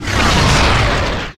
Magic_SpellPhoenix02.wav